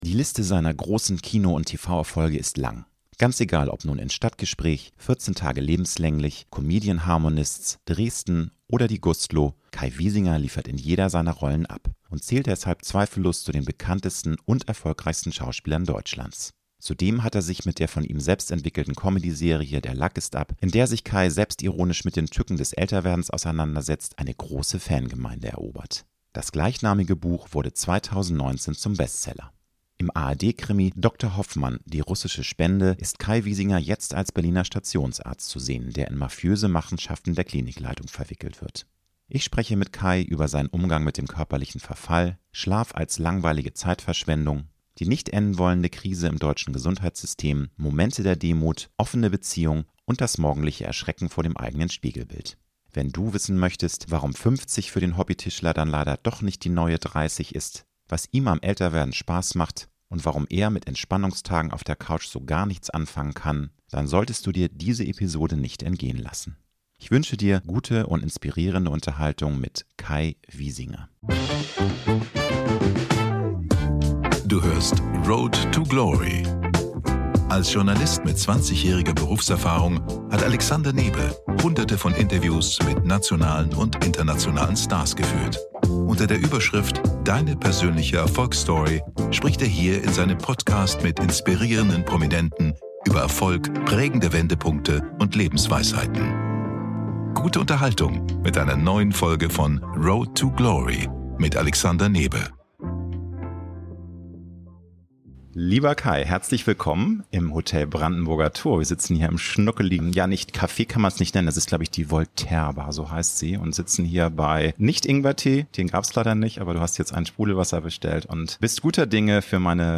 Promi-Talk